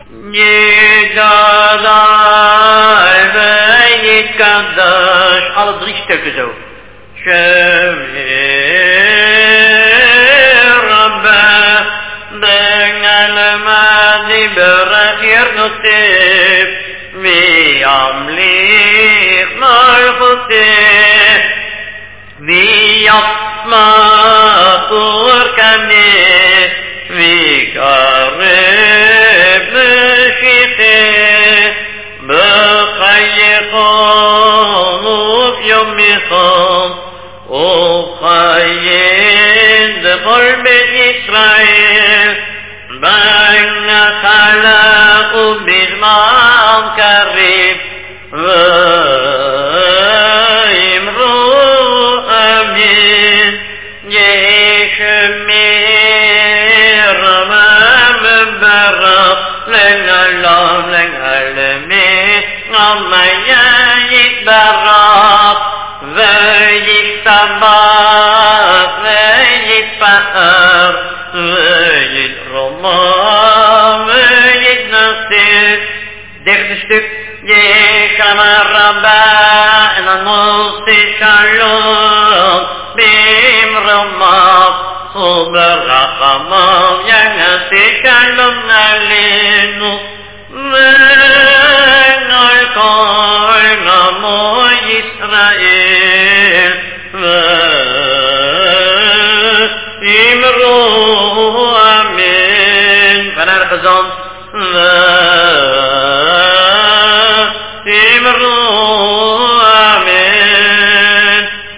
Description: 8 Sephardic tunes from Amsterdam
Communally sung during Arvit before עלינו
NN This is the basic melody for all communal singing on the High Holidays